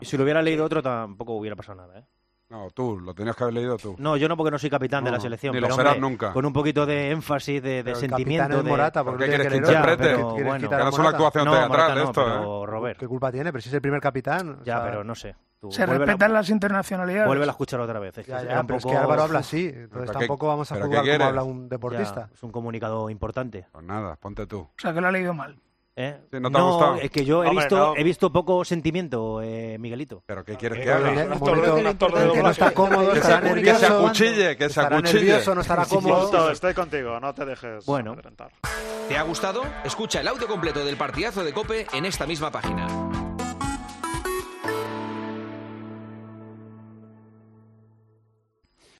Un oyente de El Partidazo de COPE explica por qué la RFEF no debe despedir a Vilda: "Hecho probado"